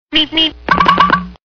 category : Sound Effects